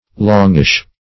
Search Result for " longish" : Wordnet 3.0 ADJECTIVE (1) 1. somewhat long ; The Collaborative International Dictionary of English v.0.48: Longish \Long"ish\, a. Somewhat long; moderately long.